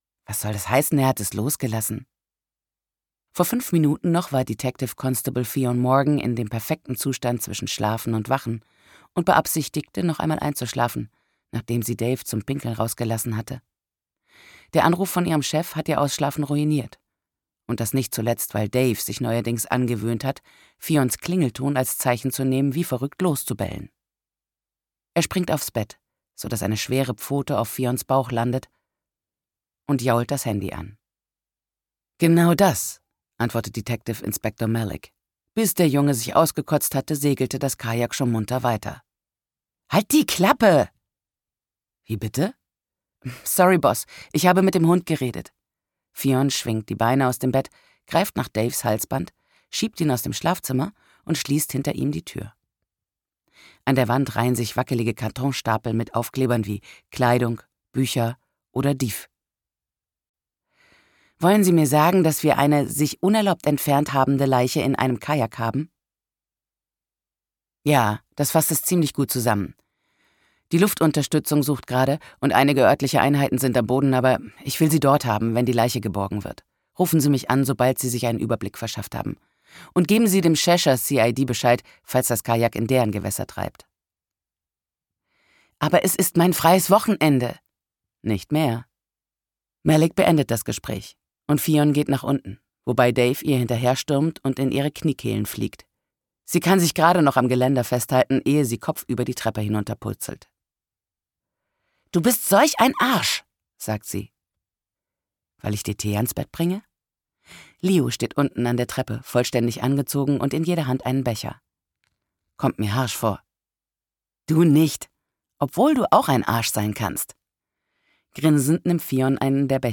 Die Toten der anderen - Clare Mackintosh | argon hörbuch
Gekürzt Autorisierte, d.h. von Autor:innen und / oder Verlagen freigegebene, bearbeitete Fassung.